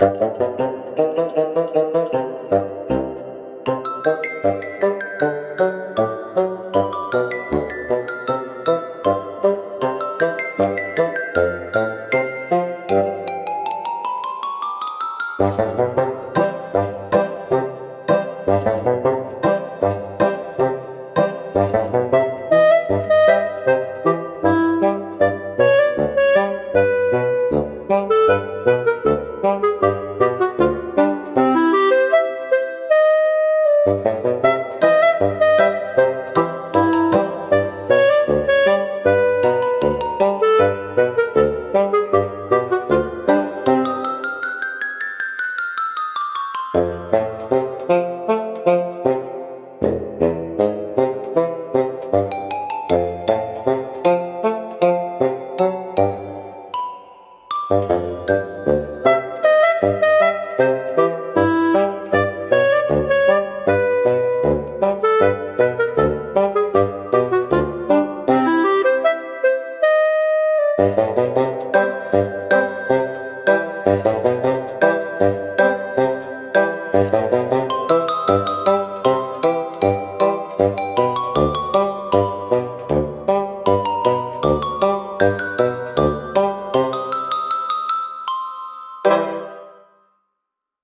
おとぼけは、バスーンとシロフォンを主体としたコミカルでドタバタした音楽ジャンルです。
視聴者の笑いを誘い、軽快で親しみやすいムードを演出しながら、退屈を吹き飛ばします。